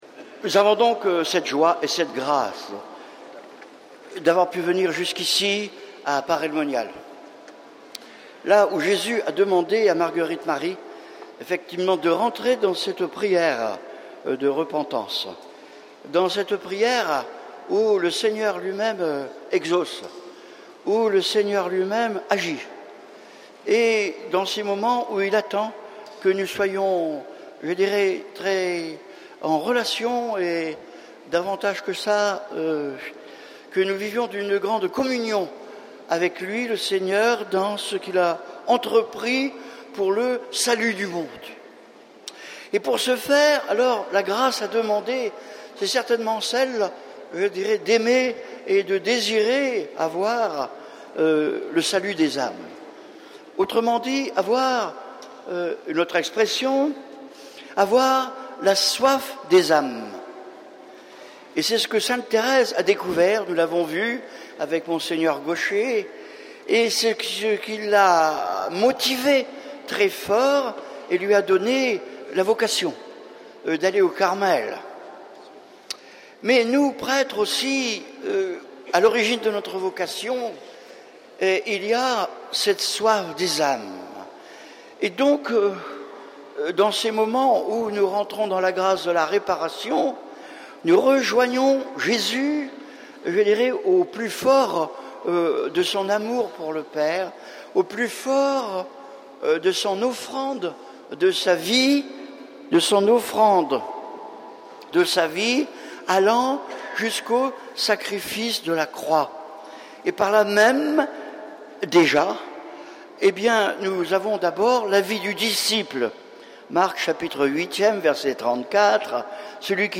Entrer dans la grande gr�ce de la r�parartion ne donne pas la tristesse mais la paix et la joie de la mis�ricorde. Rejoindre J�sus sur la Croix est la joie du pr�tre qui, � chaque messe, c�l�bre la victoire de J�sus. Enregistrement r�alis� au cours de la Retraite Sacerdotale Mondiale Ars 2005.